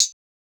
Closed Hats
La Flame Hi-Hats.wav